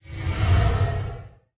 dronego.ogg